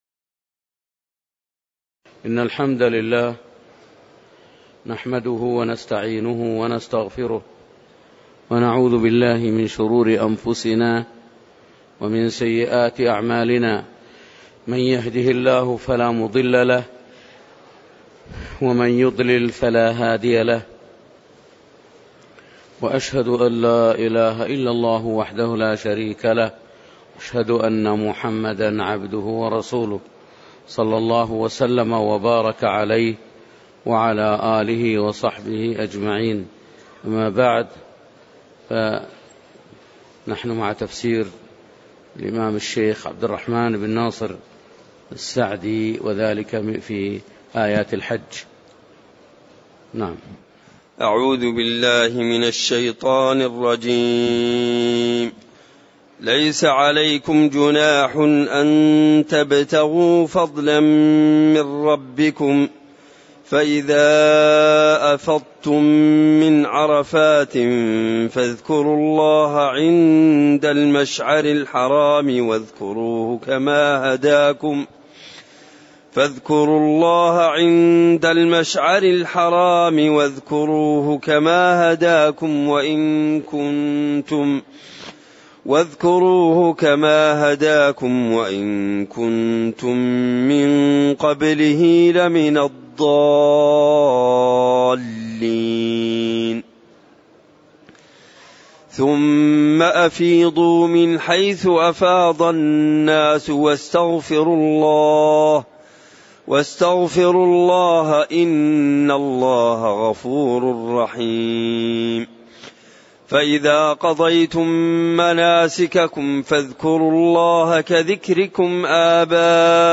تاريخ النشر ٣ ذو الحجة ١٤٣٨ هـ المكان: المسجد النبوي الشيخ